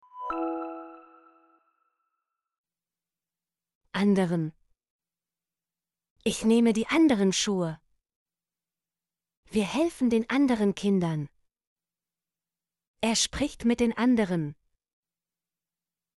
anderen - Example Sentences & Pronunciation, German Frequency List